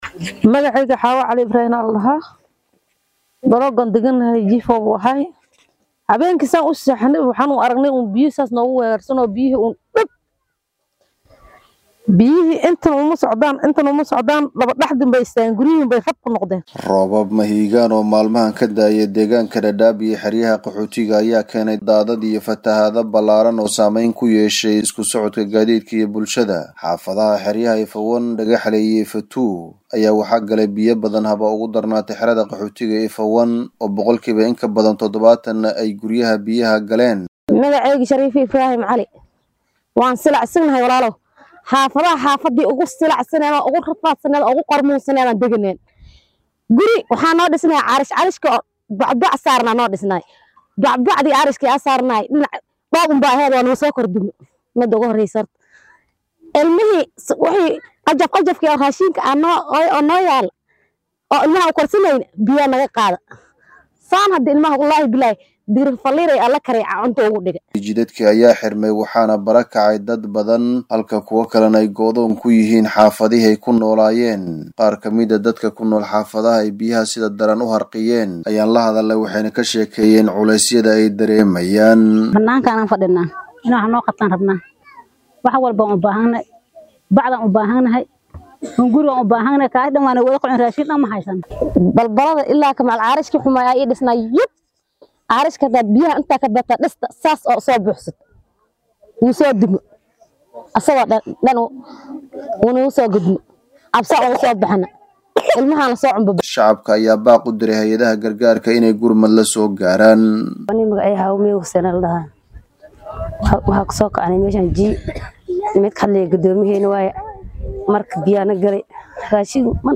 warbixintan Dadaab ka soo diray